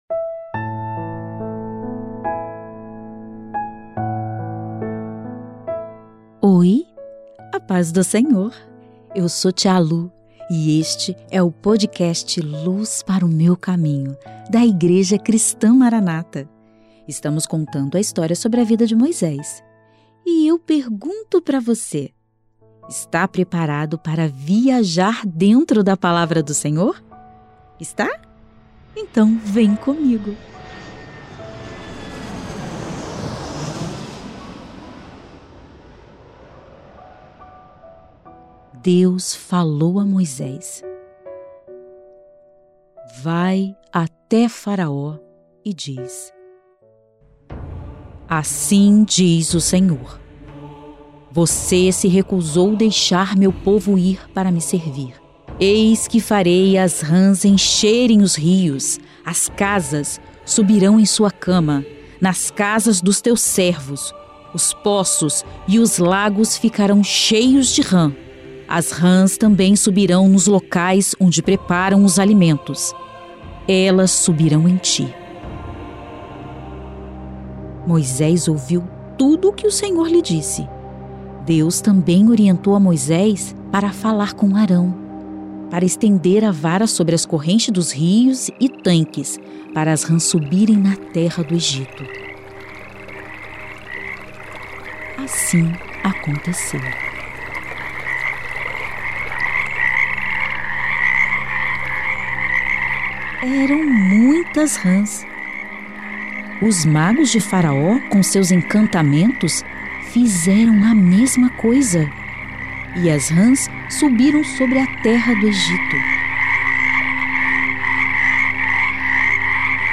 O quadro Luz Para o Meu Caminho traz histórias da bíblia narradas em uma linguagem para o público infantil.